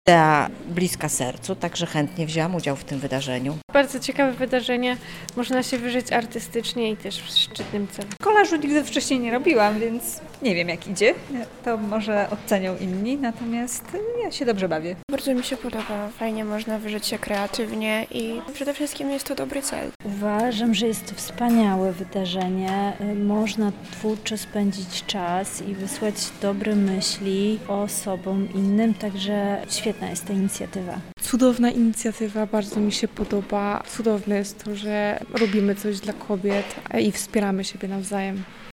Nasz reporter zapytał uczestników wydarzenia o ich opinie: